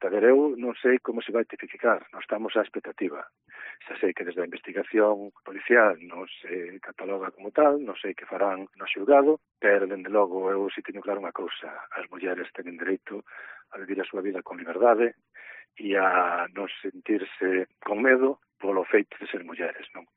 Entrevista a Xosé Manuel Fernández Abraldes, alcalde de Barro